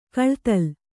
♪ kaḷtal